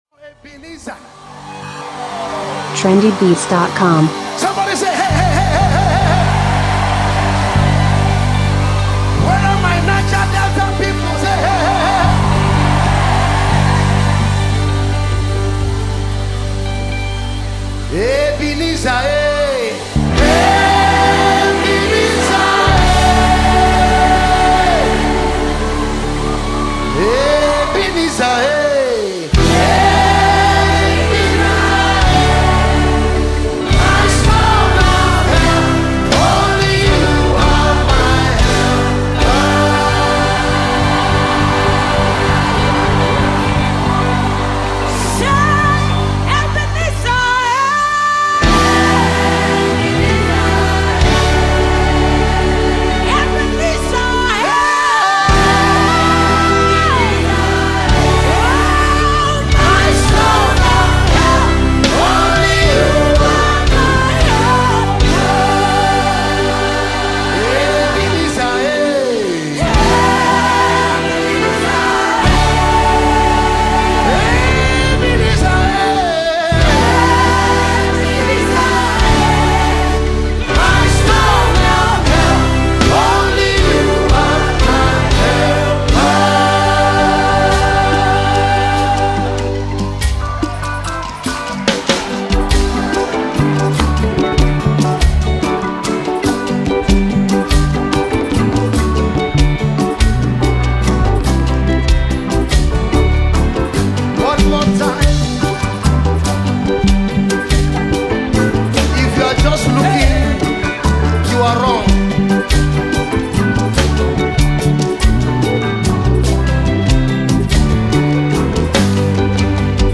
Christain Gospel